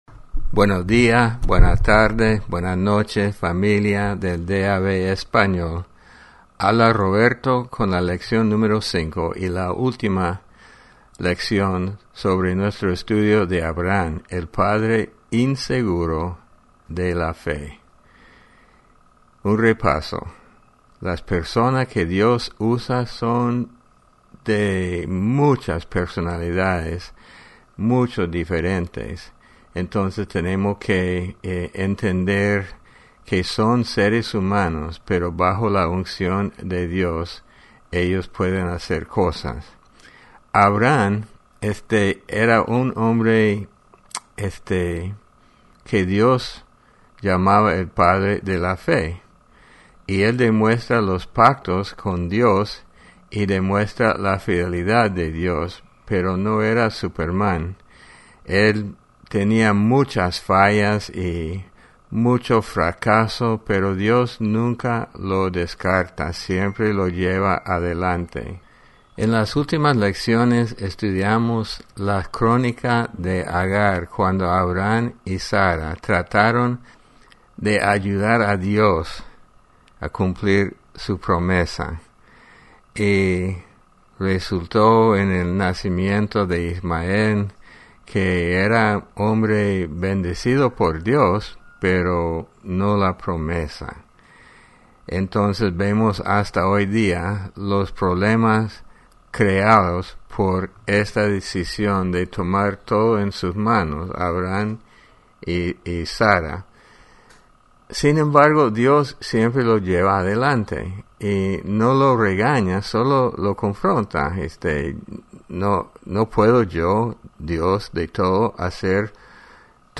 Lección #5 Abraham – El Padre inseguro de la Fe